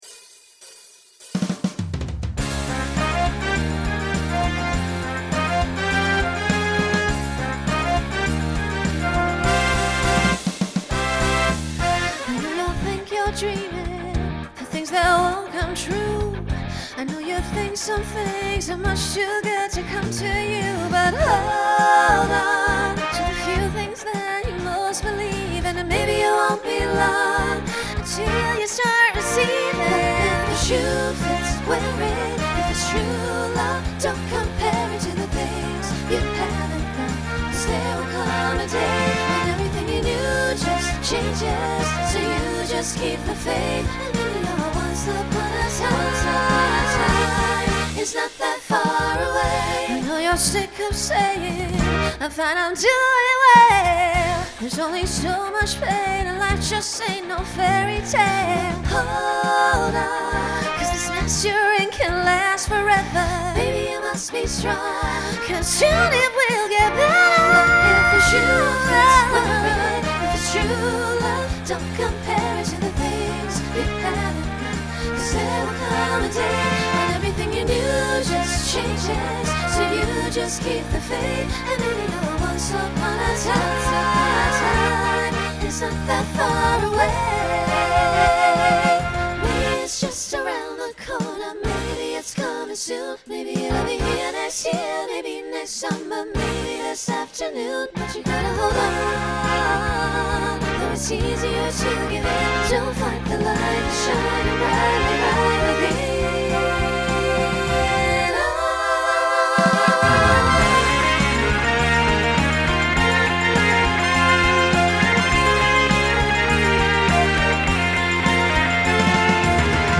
Genre Broadway/Film , Rock Instrumental combo
Story/Theme Voicing SSA